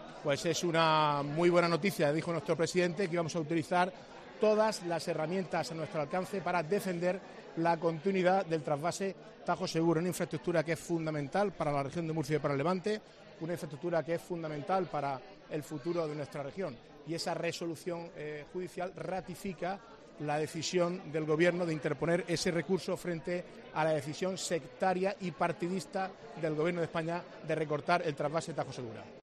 Marcos Ortuño califica de buena noticia el anuncia del Tribunal Supermo